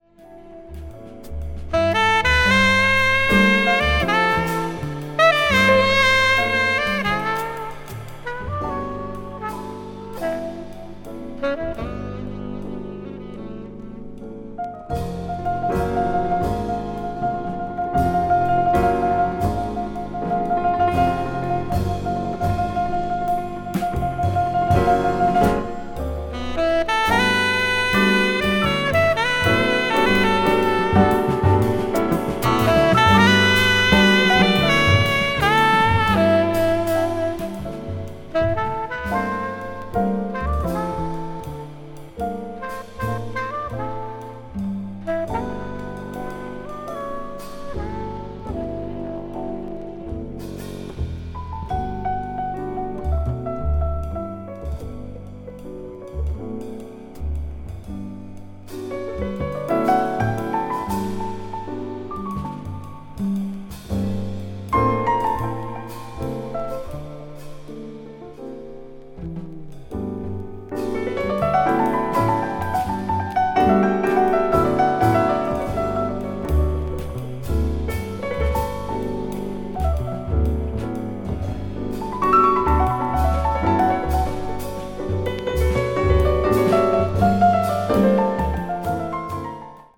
contemporary jazz   deep jazz   modal jazz   spiritual jazz